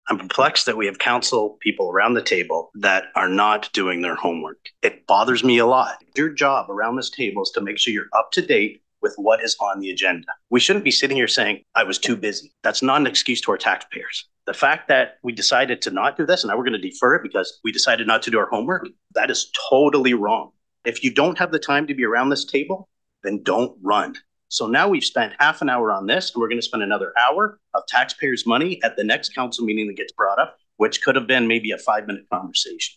Sparks flew in Bluewater council chambers on Monday night (June 18) when councillors discussed the municipality’s alcohol policy.